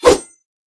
knife_slash2.wav